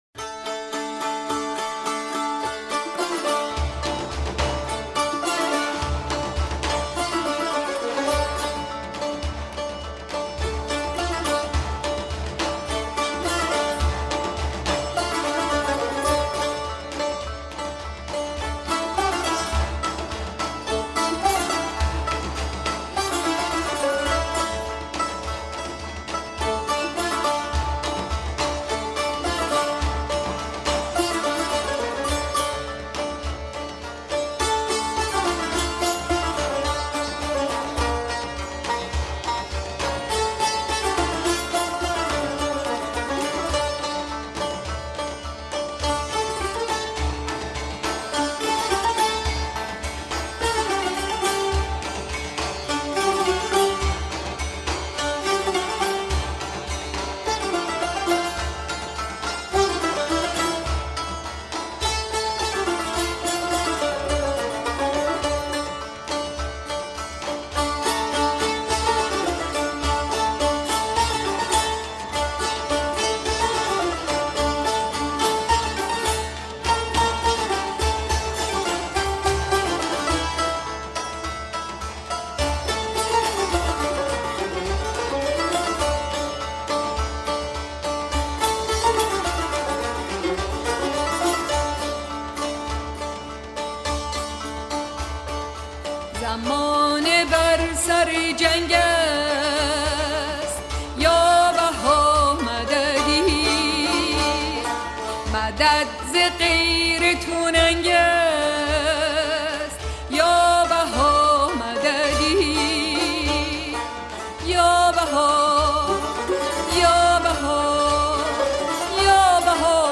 سرود - شماره 6 | تعالیم و عقاید آئین بهائی
مجموعه ای از مناجات ها و اشعار بهائی (سنتّی)